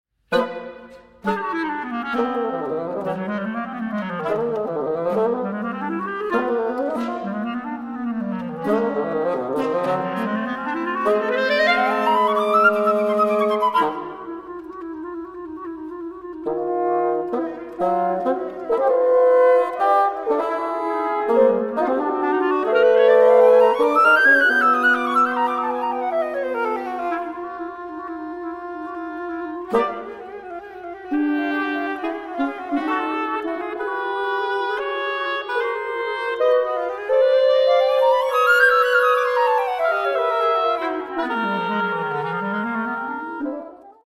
chamber works for various instrumentations
I. Presto energico